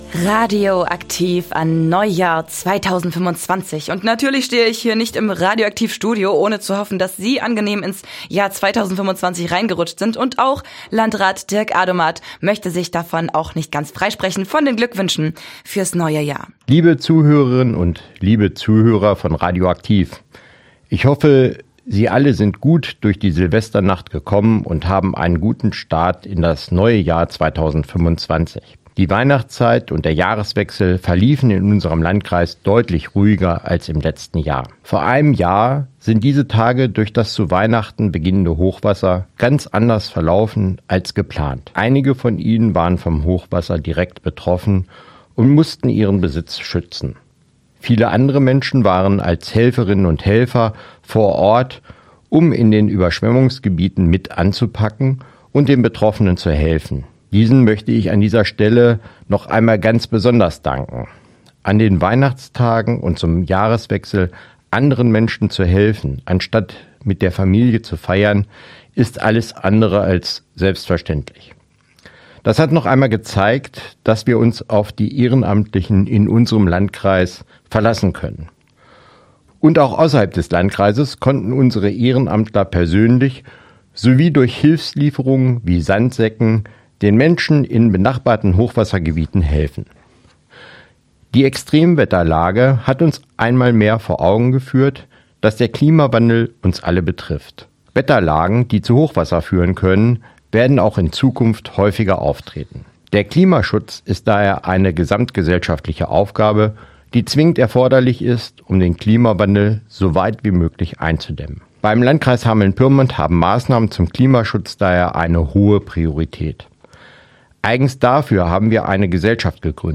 Hameln-Pyrmont: Neujahrsansprache von Landrat Dirk Adomat bei radio aktiv
hameln-pyrmont-neujahrsansprache-des-landrats.mp3